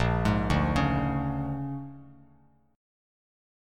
A#7#9 chord